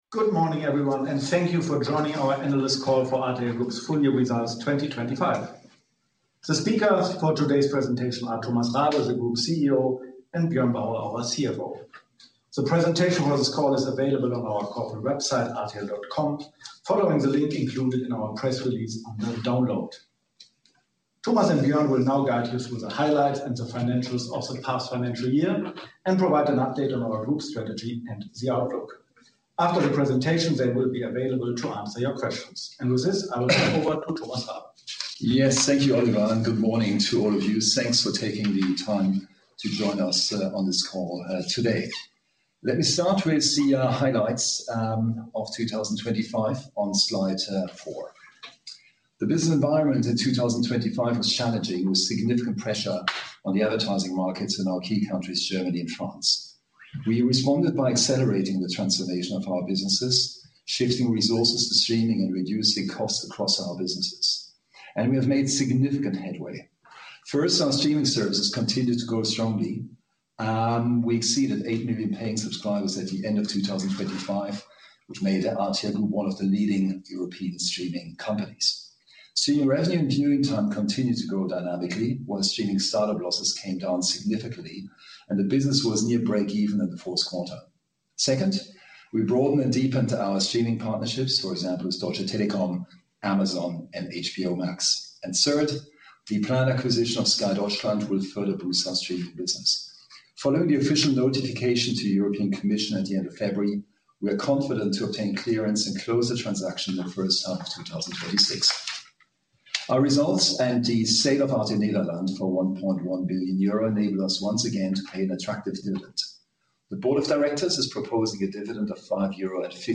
Analyst call
rtl-group-fy-2025-analyst-call.mp3